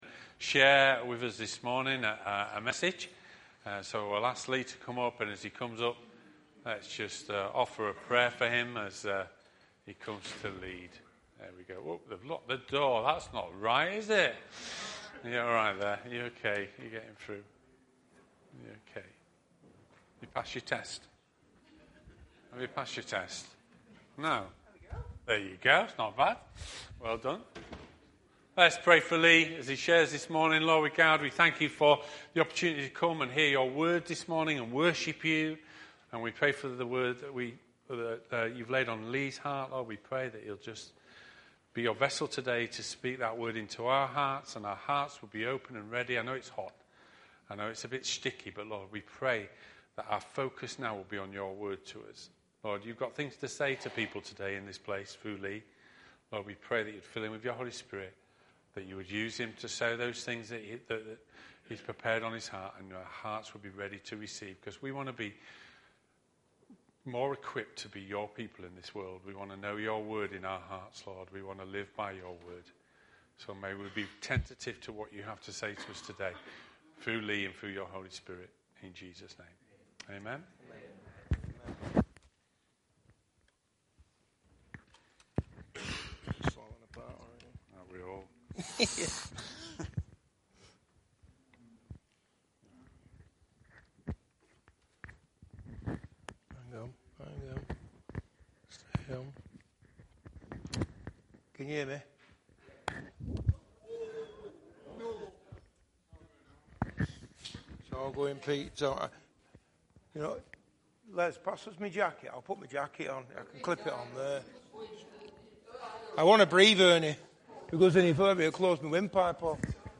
Sermons - Chadderton Community Church